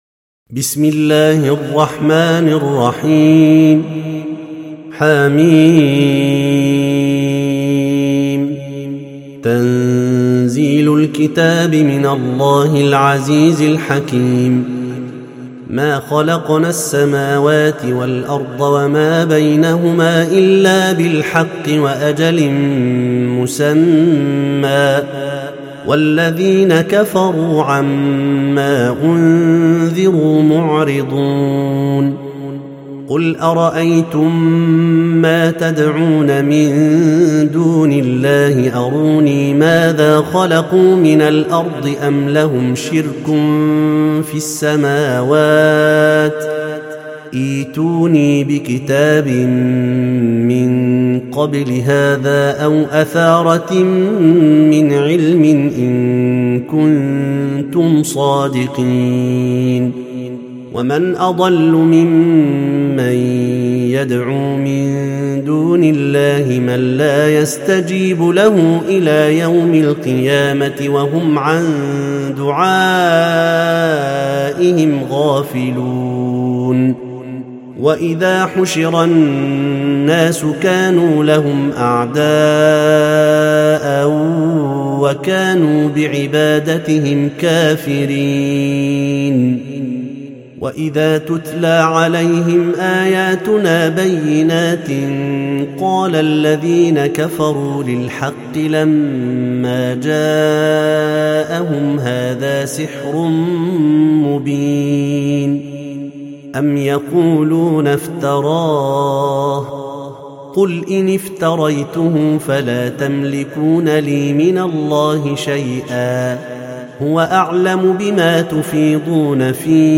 سورة الأحقاف - المصحف المرتل (برواية حفص عن عاصم)